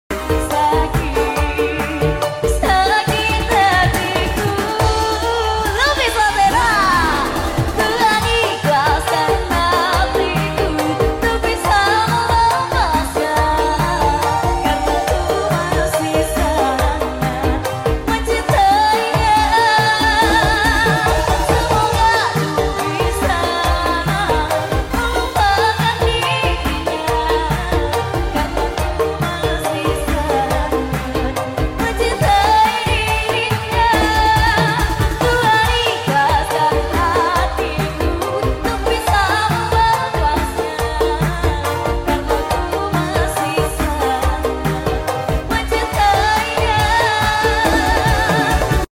suaranya khas banget 🥰